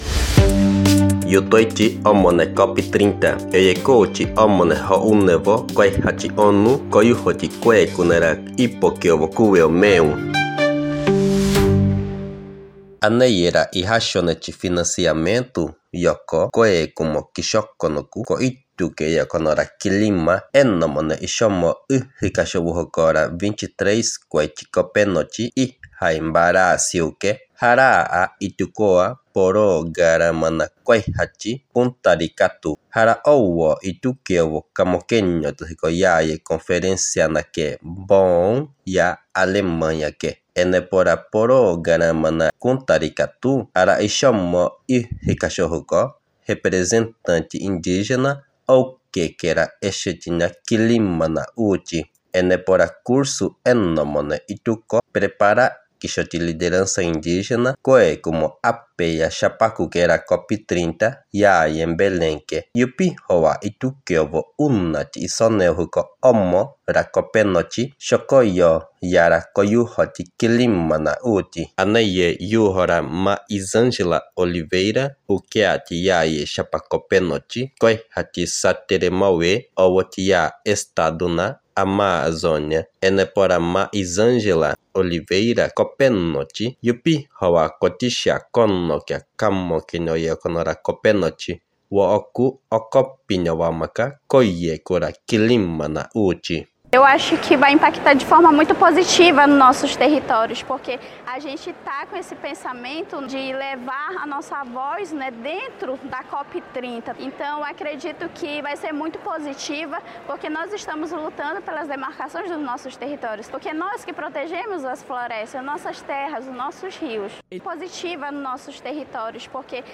Boletins na língua indígena Terena são traduzidos e grvados em parceria com a Universidade Federal da Grande Dourados (UFGD), do estado do Mato Grosso do Sul.